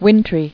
[win·try]